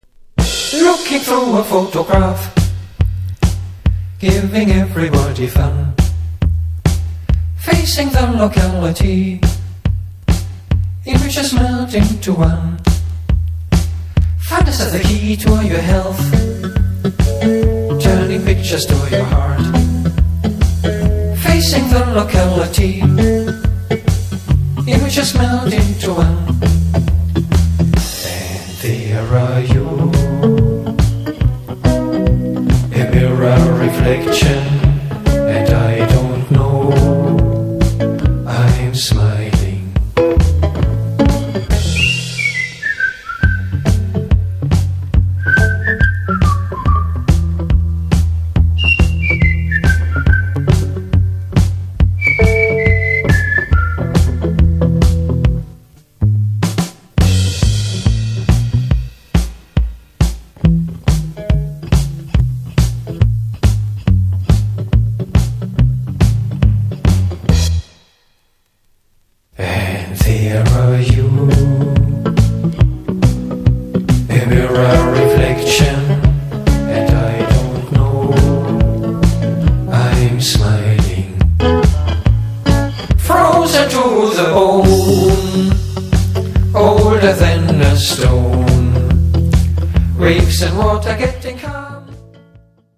口笛入りお惚け鼻歌系フェイク・スイング♪